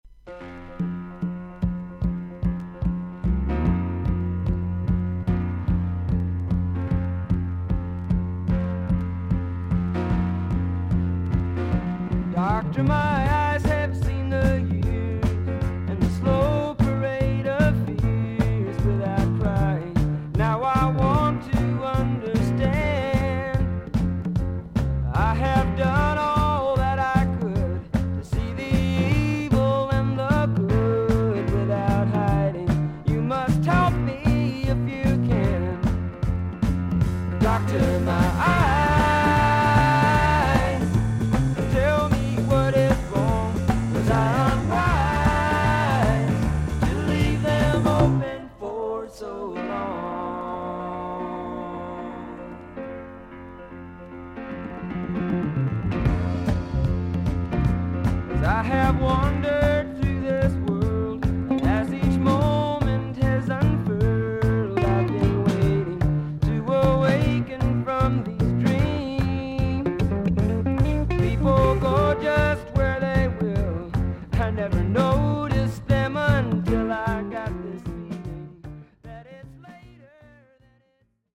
アメリカ盤 / 12インチ LP レコード / ステレオ盤
少々軽いパチノイズの箇所あり。少々サーフィス・ノイズあり。クリアな音です。